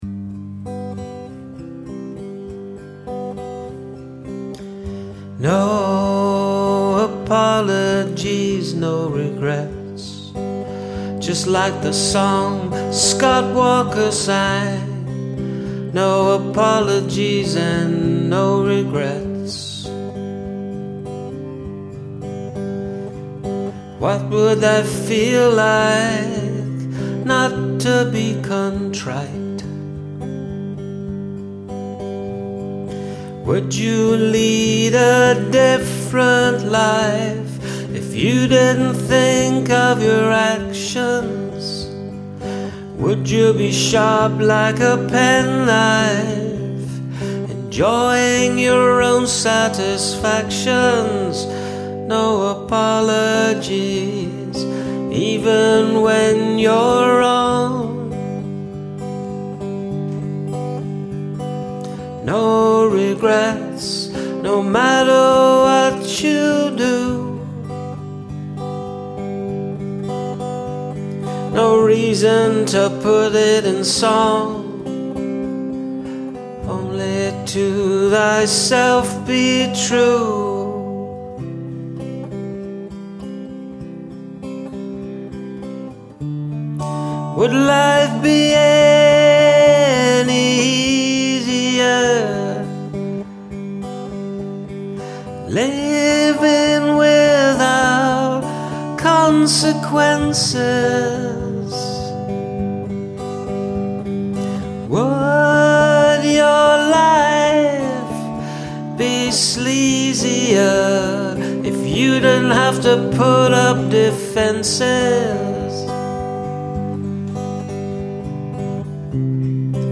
Thats a very soft and heartfelt delivery.